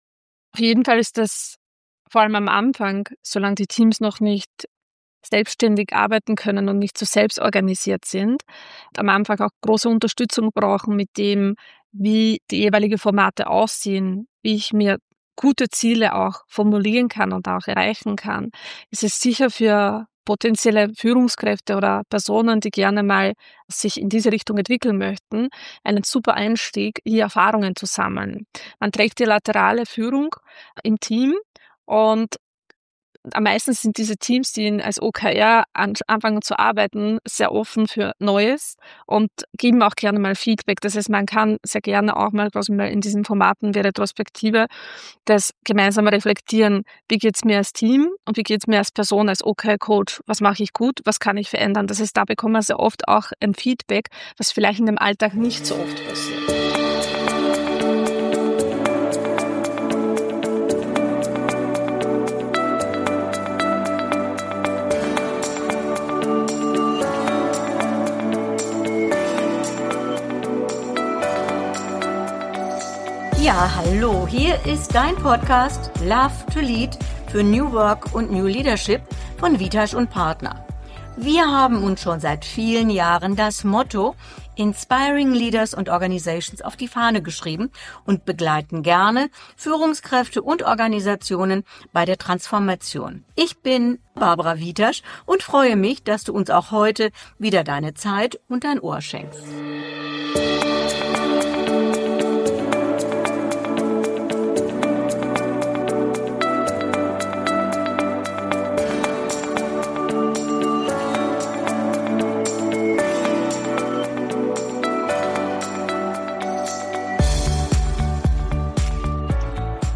Ein Gespräch über Wirkung statt Aktionismus – und über Führung, die Menschen bewegt.